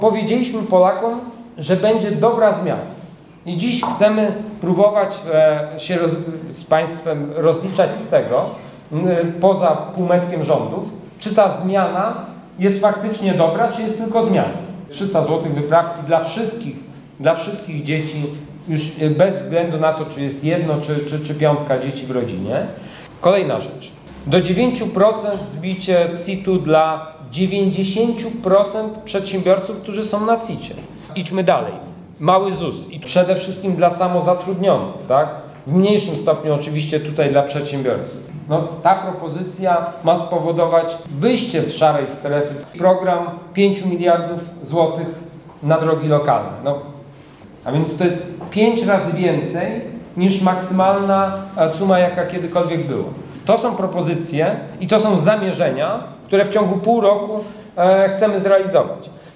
– Chcemy rozliczać się z dobrej zmiany – dodaje Łukasz Schreiber.